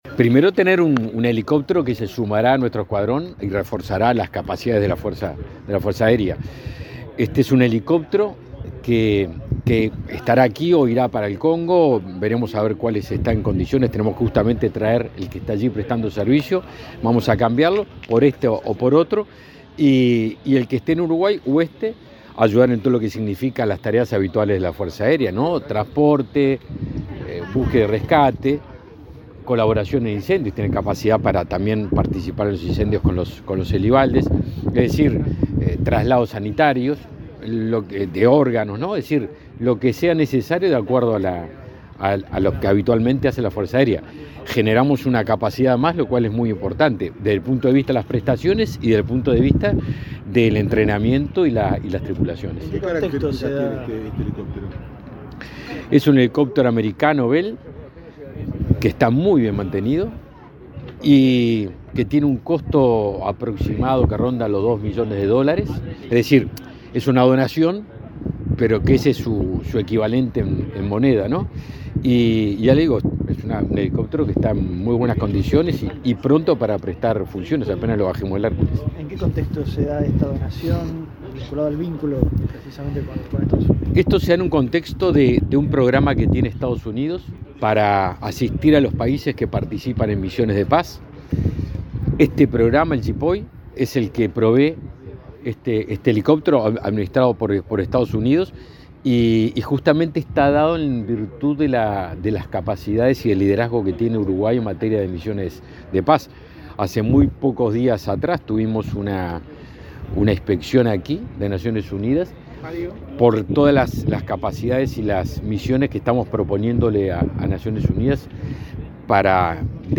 Declaraciones del ministro de Defensa Nacional, Javier García
Luego dialogó con la prensa.